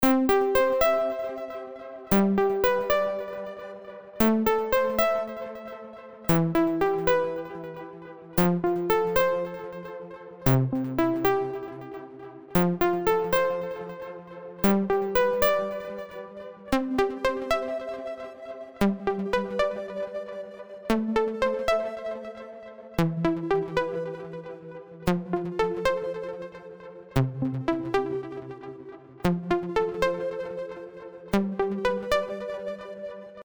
Da gibt es dann doch erhebliche Unterschiede. z.B beim Preset NoiseDropz: Erste hälfte FM7 zweite hälfte FM8.